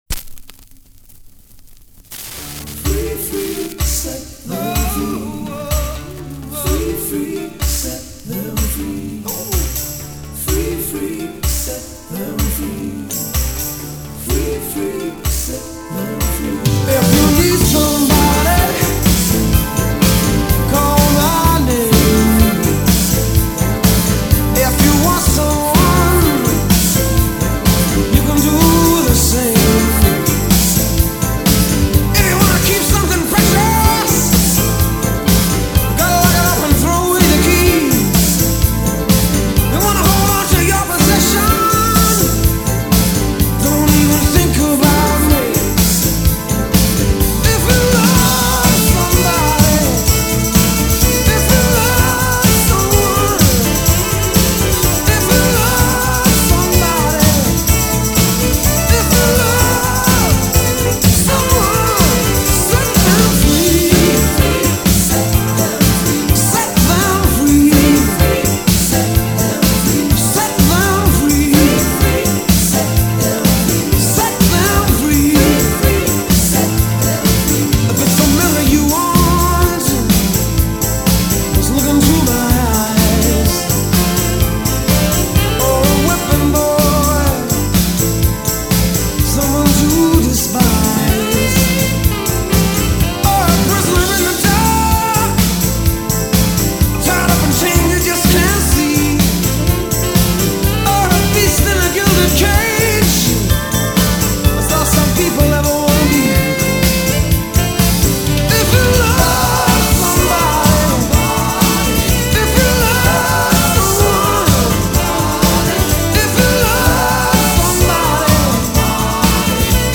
Pop, Jazz, Rock